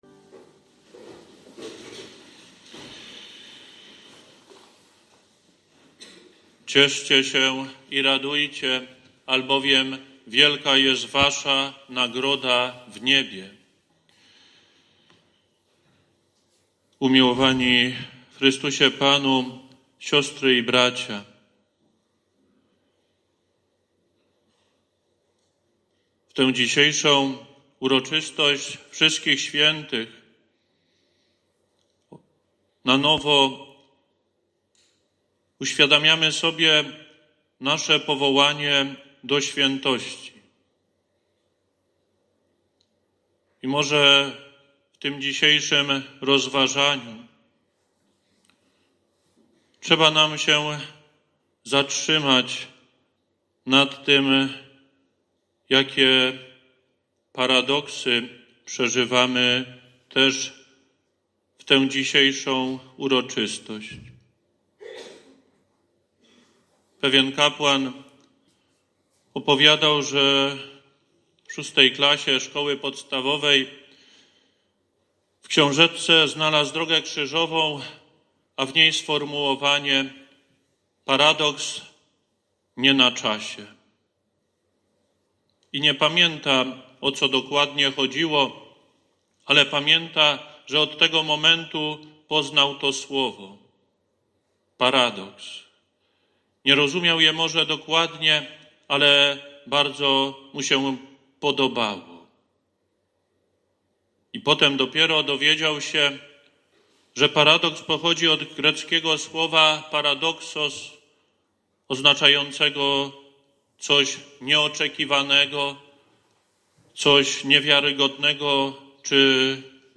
KAZANIA DO SŁUCHANIA - OKOLICZNOŚCIOWE